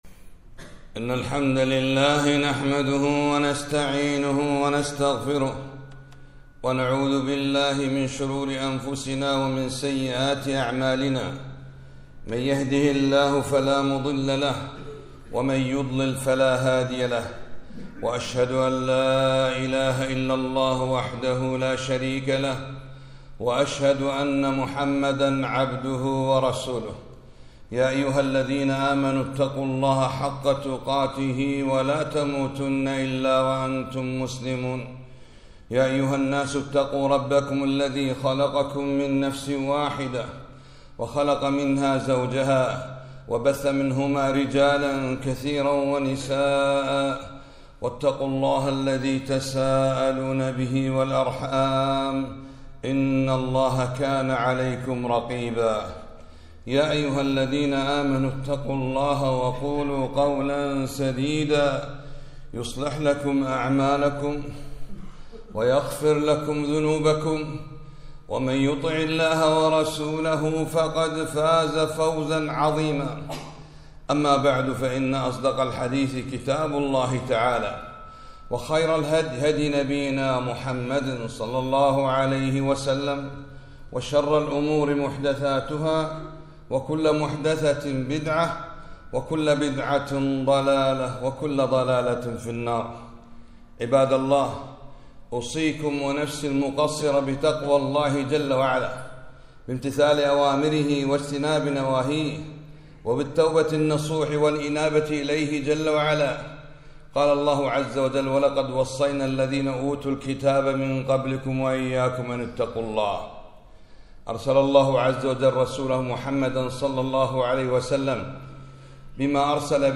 خطبة - هكذا كانت رسالة النبي ﷺ